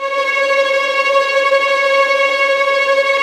Index of /90_sSampleCDs/Roland L-CD702/VOL-1/STR_Vlns Tremelo/STR_Vls Trem wh%